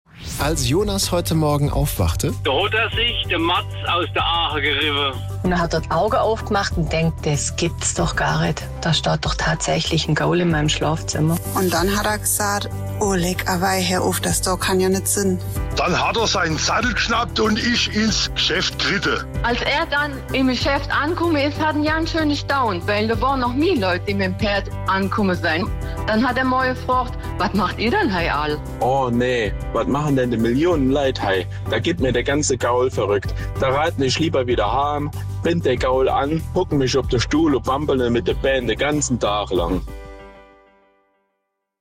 Wir schreiben eine kleine Geschichte mit möglichst vielen Dialekten aus SWR3Land.